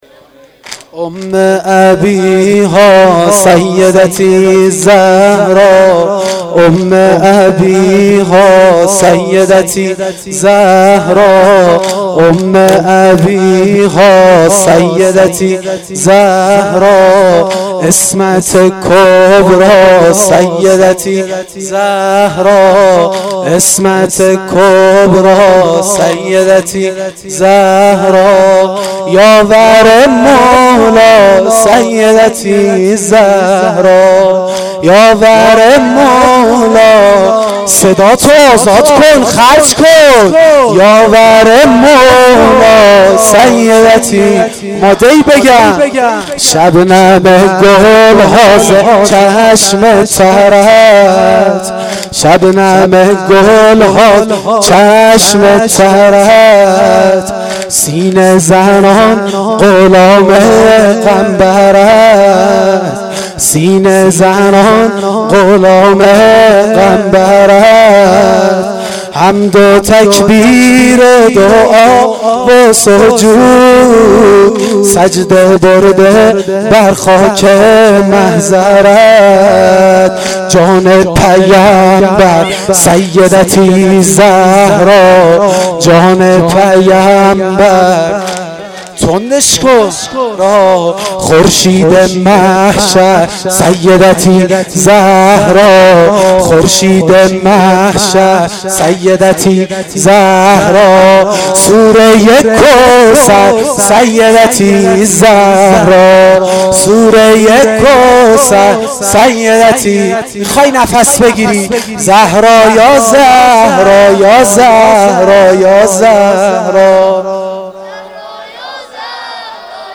واحد شب اول فاطمیه دوم